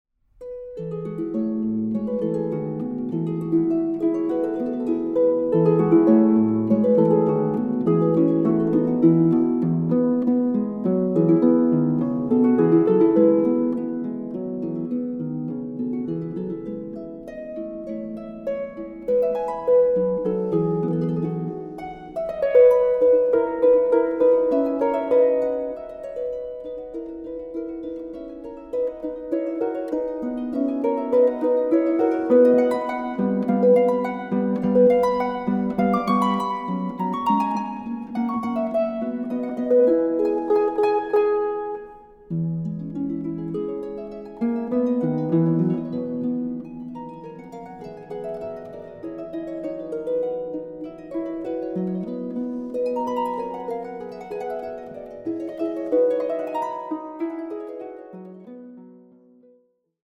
Harp
Recording: Festeburgkirche Frankfurt, 2024